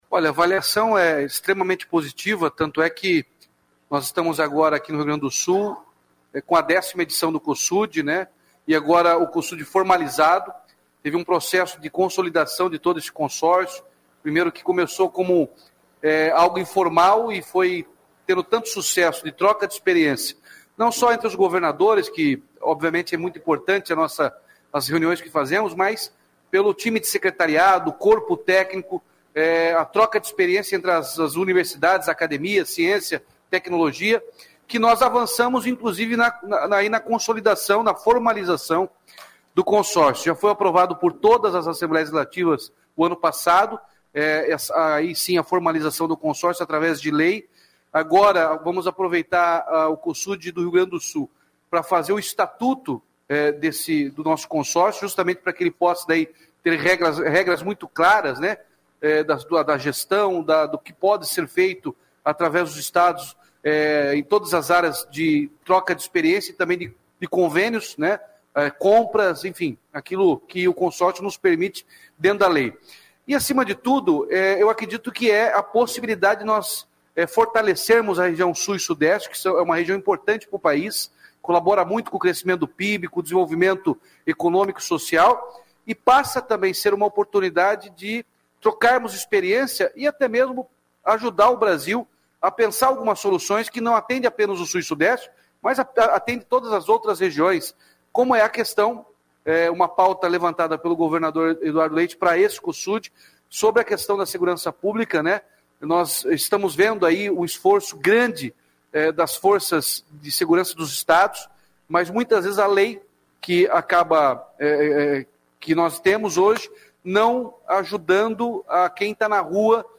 Sonora do governador Ratinho Junior sobre a cerimônia de abertura do Cosud